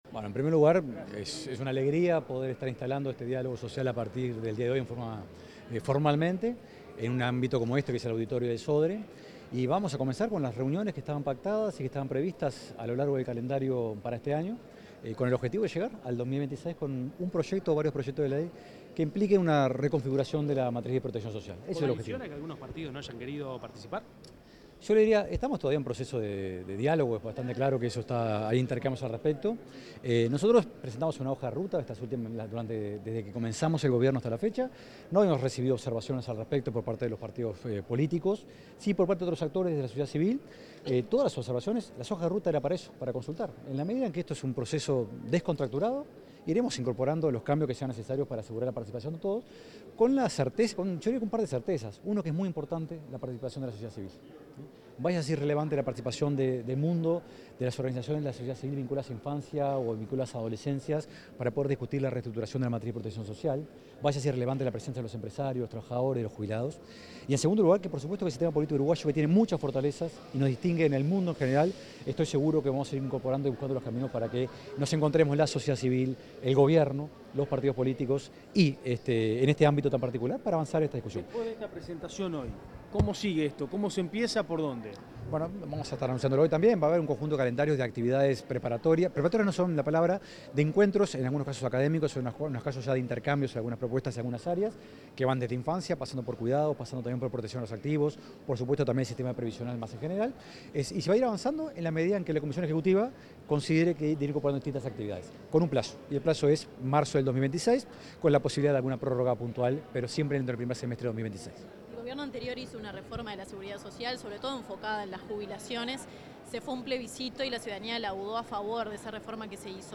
Declaraciones del director de la OPP, Rodrigo Arim
El director de la Oficina de Planeamiento y Presupuesto (OPP), Rodrigo Arim, dialogó con la prensa en el lanzamiento del Diálogo Social sobre